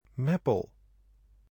Meppel (Dutch pronunciation: [ˈmɛpəl]
Nl-Meppel.oga.mp3